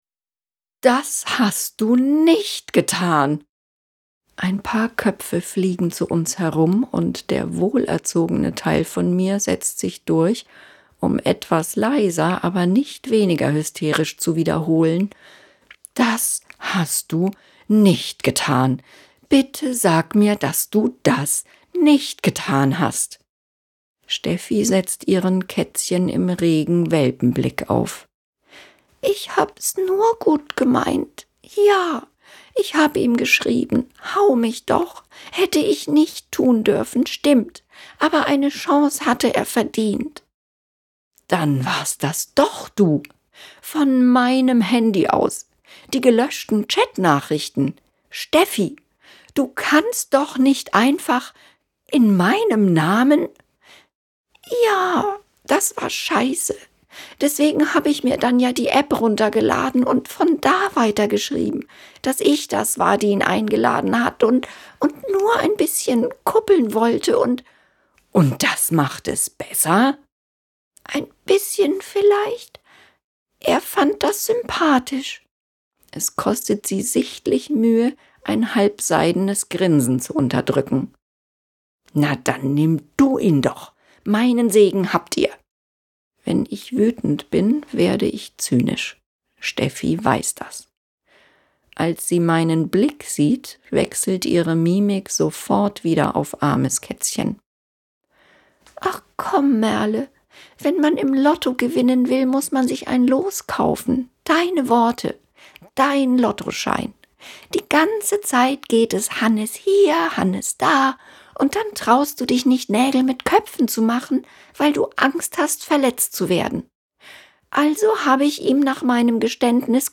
Mit ihrer wandlungsfähigen, lebendigen Stimme fesselt sie ihre Zuhörerschaft durch alle Genres, Sparten und Formate - von Live-Lesungen bis Hörbuch, von Dokumentation bis Synchron, von emotional bis sachlich.